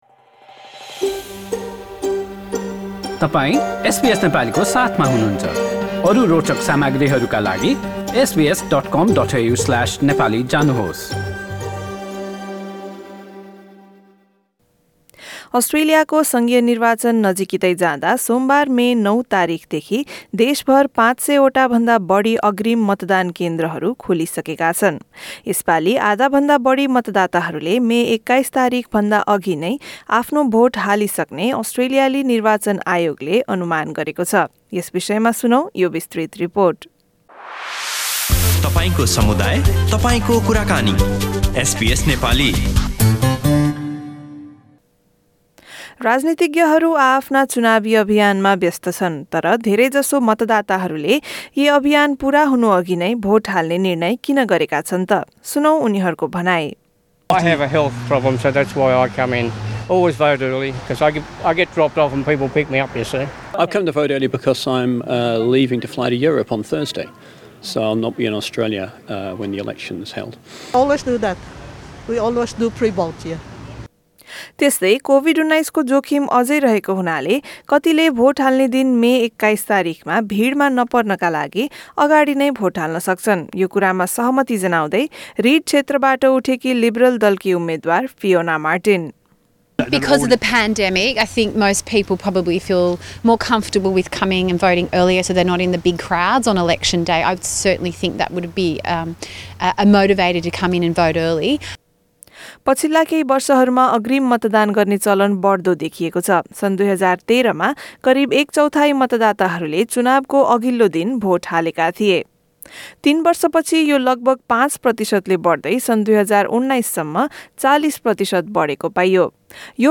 मतदाताहरूको भनाइ सहितको रिपोर्ट यहाँ सुन्नुहोस्: सङ्घीय निर्वाचन २०२२: प्रारम्भिक मतदान सुरु हाम्रा थप अडियो प्रस्तुतिहरू पोडकास्टका रूपमा उपलब्ध छन्।